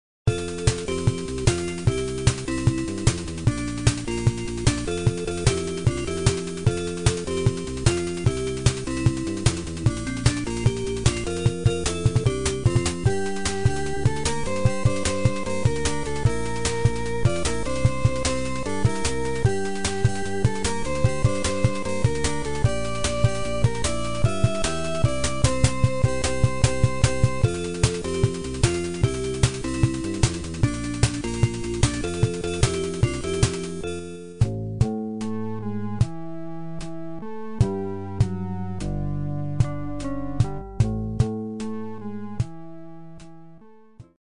tira vantagem do chip de som FM